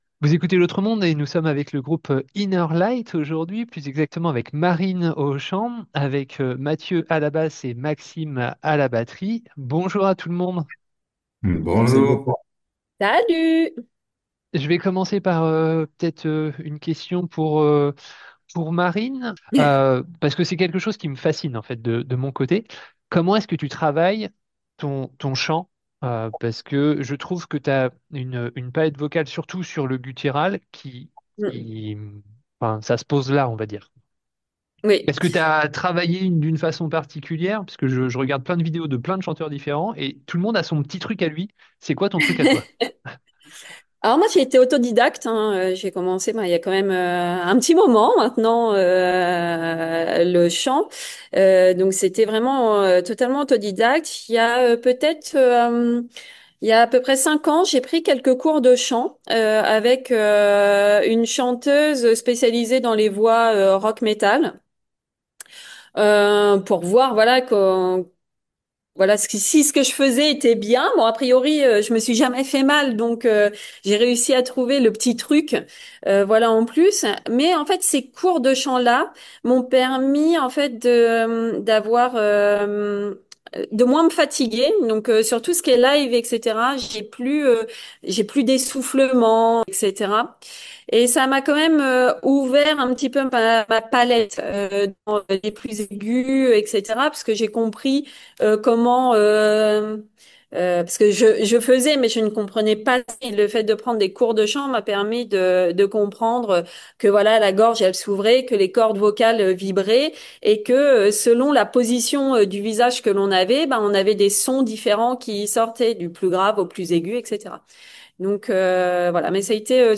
interview du groupe INNER LIGHT pour la sortie de leur album NOVA.